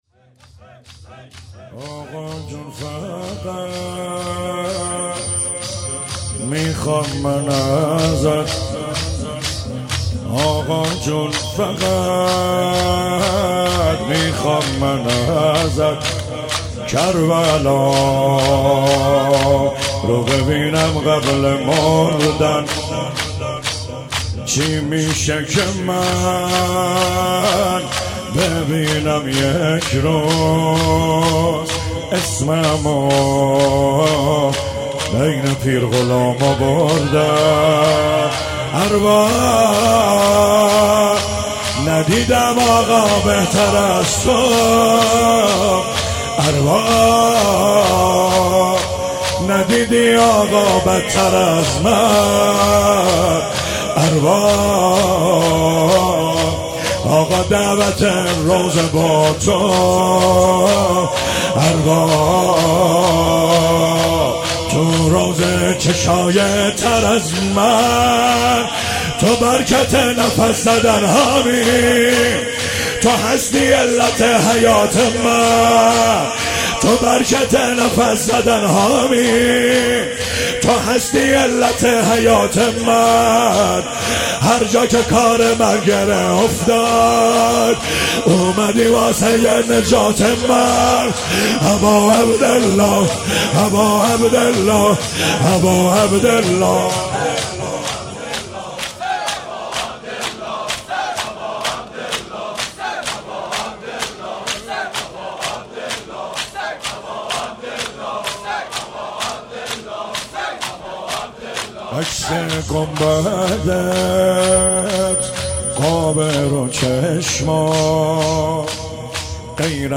مداحی جدید
شور